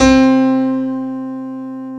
Index of /90_sSampleCDs/Best Service ProSamples vol.10 - House [AKAI] 1CD/Partition D/SY PIANO